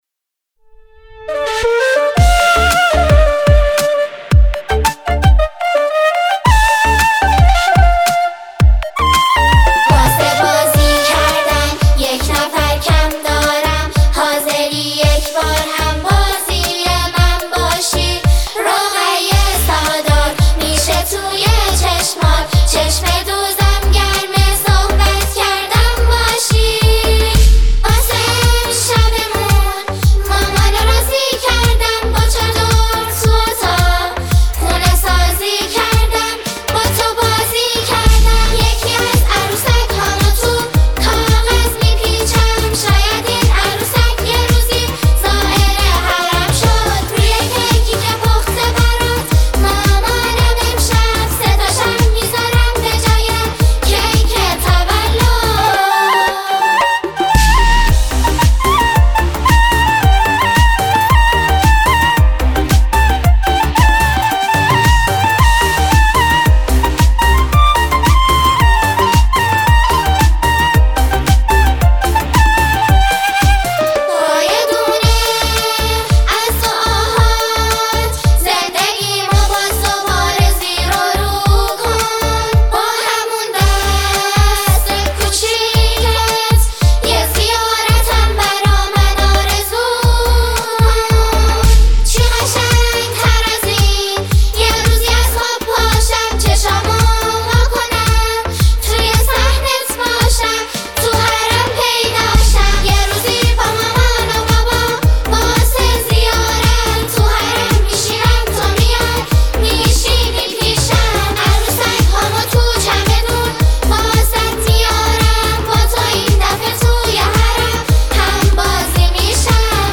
یک اثر لطیف، کودکانه و پر احساس
گروه سرود دخترانۀ